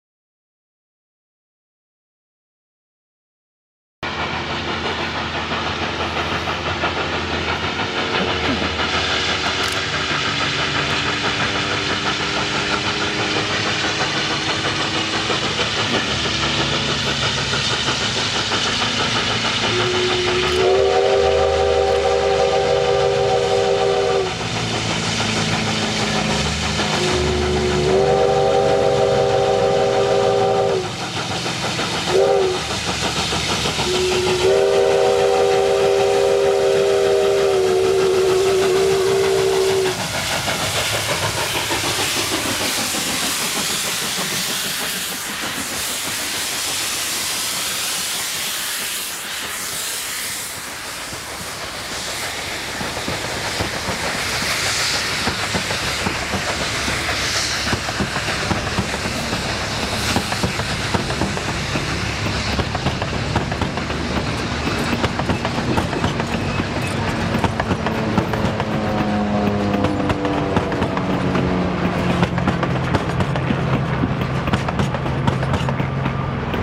Cumbres and Toltec Double-Header Leaving Chama, New Mexico